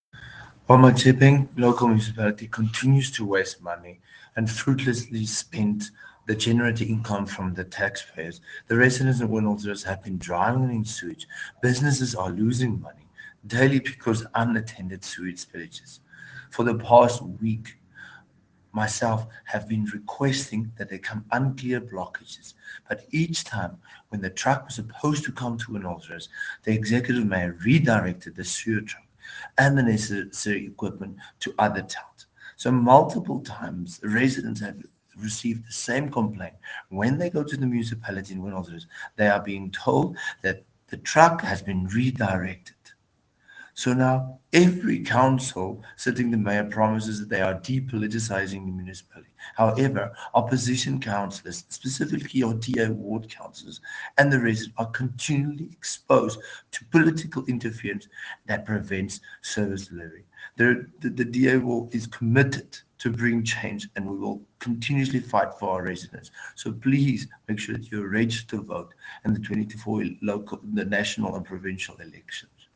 Afrikaans soundbites by Cllr Igor Scheurkogel and